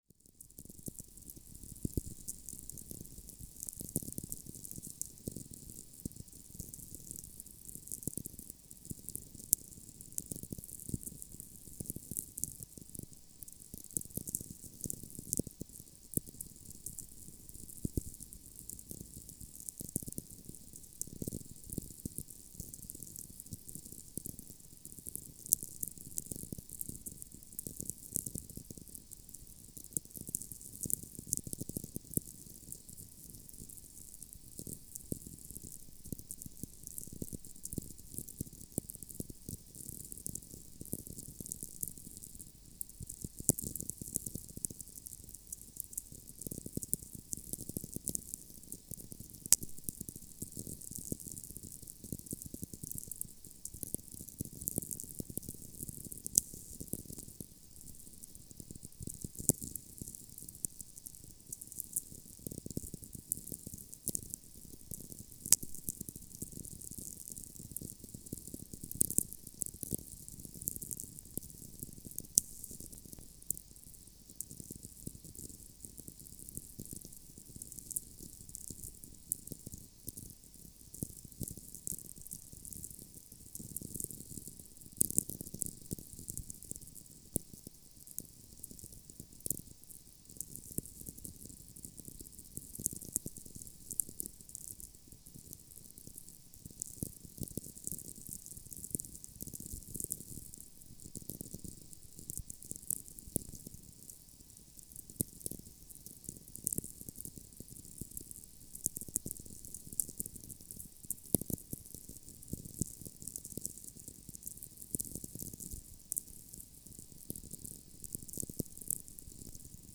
Space weather over Iceland March 22, 2025
However, it is using a 75cm whip antenna that can be connected to the receiver in an instant via BNC connector.
It reaches lower frequencies or below 10Hz and seems to reach much higher frequencies than 24Khz.